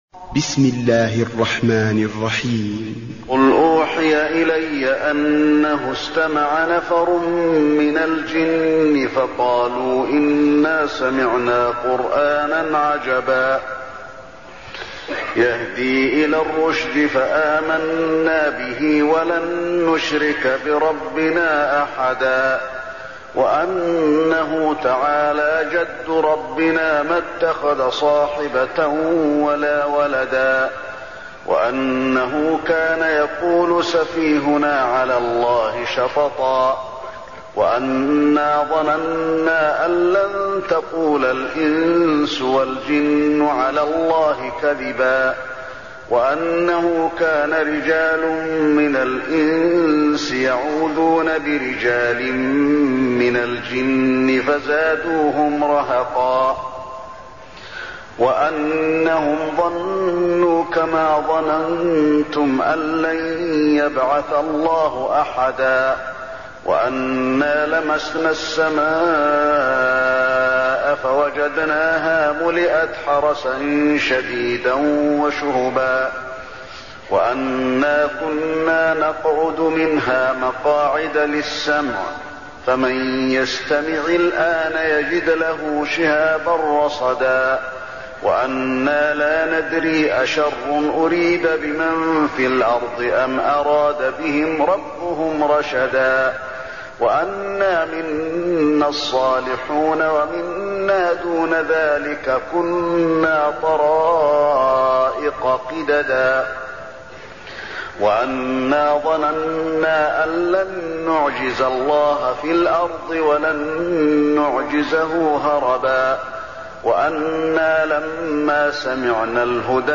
المكان: المسجد النبوي الجن